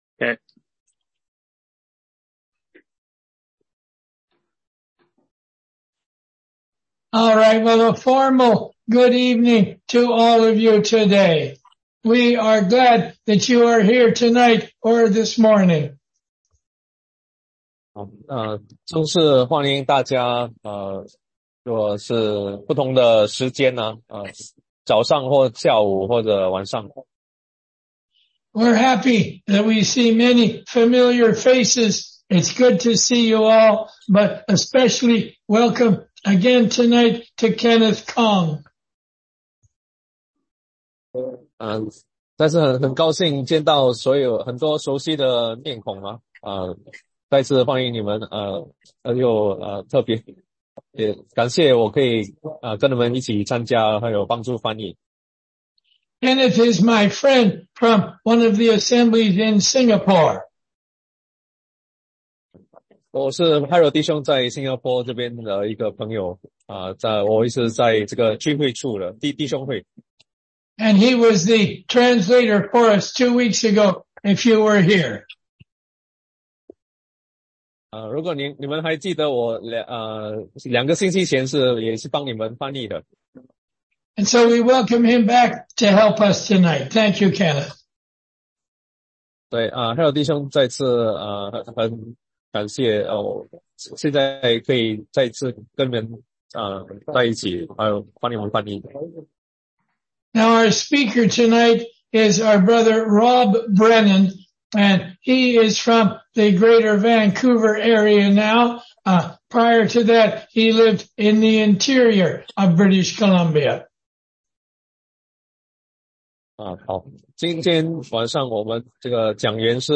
16街讲道录音 - 民数记22-24章——巴兰的教训
中英文查经